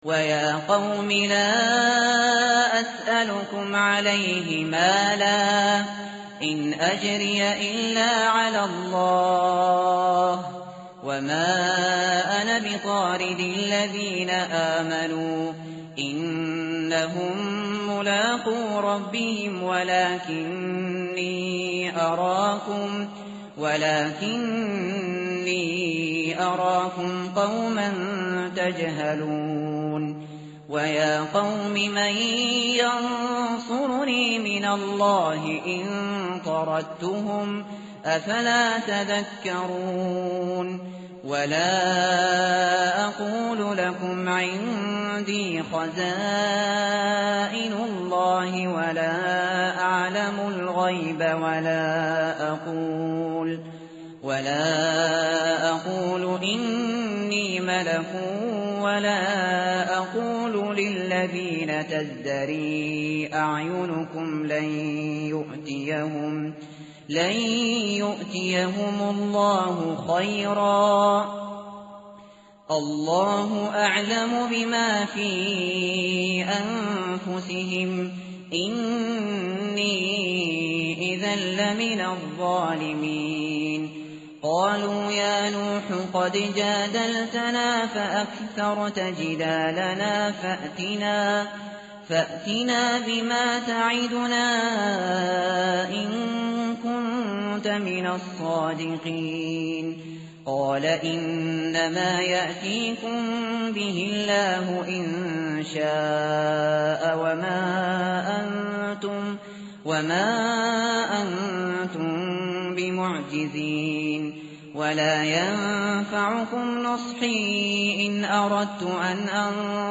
tartil_shateri_page_225.mp3